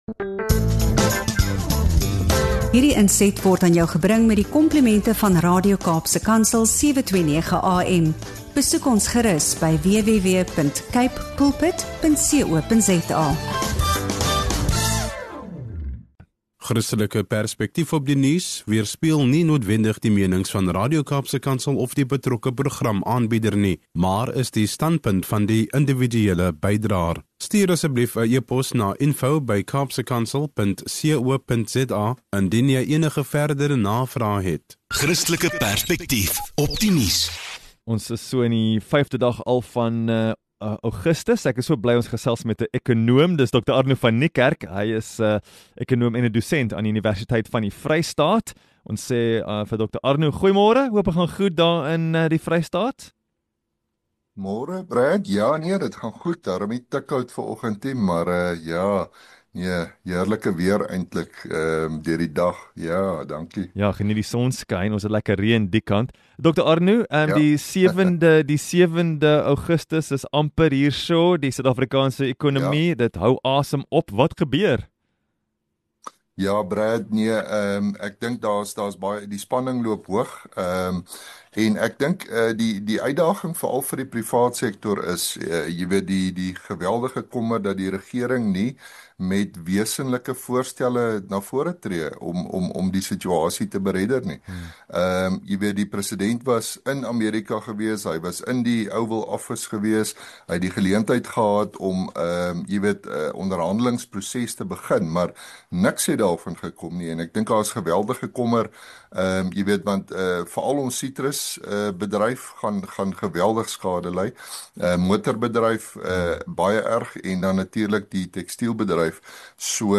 Hierdie insiggewende gesprek belig hoe geloof en gebed ’n sleutelrol kan speel in tye van ekonomiese druk en wêreldwye onrus.